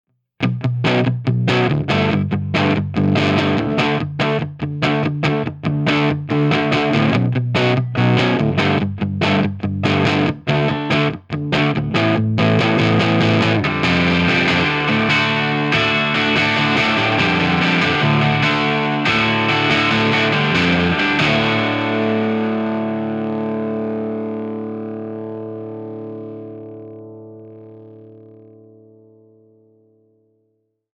JTM45 Dirty Marshall G12T-75
What makes this clip difficult is some speakers work well with the PM's in the first half while others handle the open chord work much better.
JTM_DIRTY_MarshallG12T75.mp3